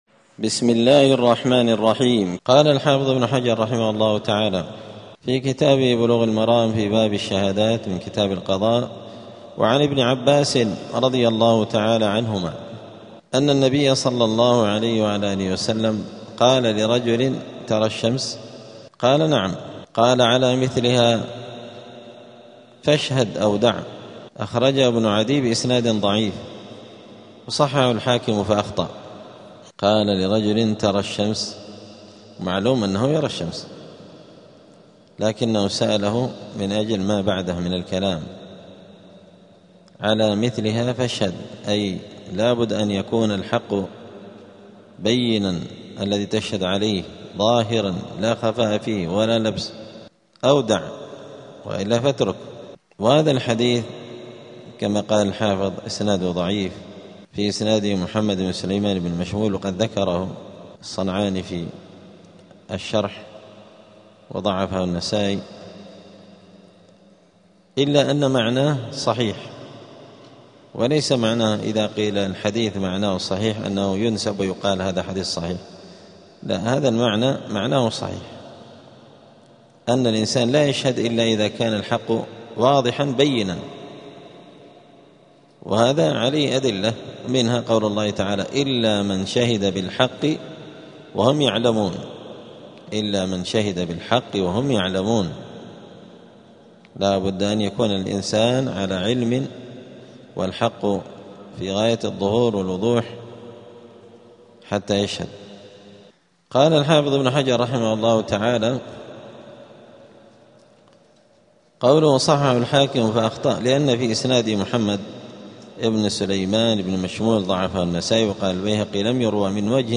*الدرس العشرون (20) {اﻟﺸﻬﺎﺩﺓ ﻋﻠﻰ ﻣﺎ اﺳﺘﻴﻘﻦ ﻭﺑﺎﻻﺳﺘﻔﺎﺿﺔ}*
دار الحديث السلفية بمسجد الفرقان قشن المهرة اليمن